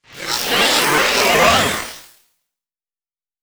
Techno / Voice
1 channel